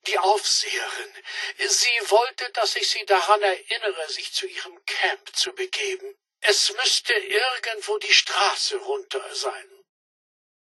Fallout 76: Audiodialoge